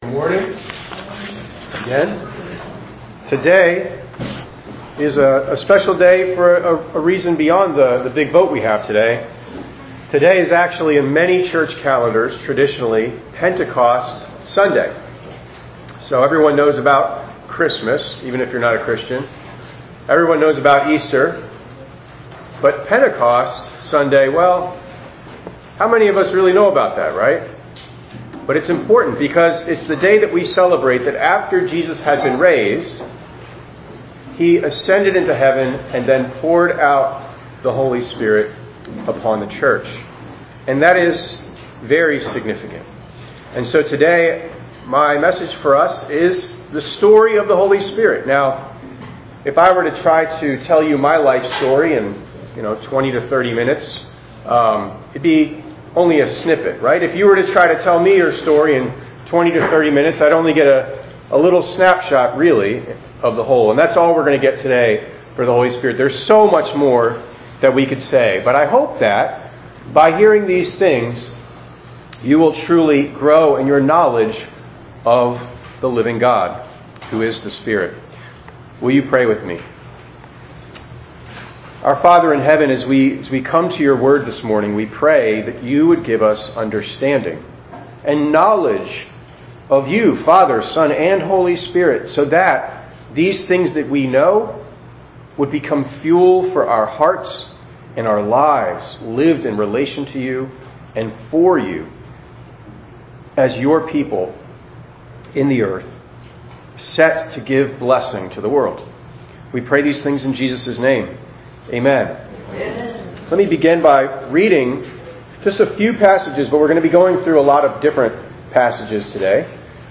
6_8_25_ENG_Sermon.mp3